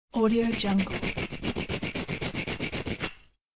دانلود افکت صوتی تمیز کردن با برس1
تراک صوتی رایگان Pumice Scrubbing Cleaning 1 یک گزینه عالی برای هر پروژه ای است که به صداهای داخلی و جنبه های دیگر مانند sfx، صدا و کارهای روزمره نیاز دارد.
این فایل صوتی، که توسط متخصصین صداگذاری طراحی شده، با دقت فراوانی صدای برس کشیدن بر روی سطوح مختلف را ضبط کرده است تا تجربه‌ای غنی و واقعی را برای شنونده فراهم آورد.
Sample rate 16-Bit Stereo, 44.1 kHz
Looped No